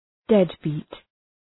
Shkrimi fonetik {‘ded,bi:t}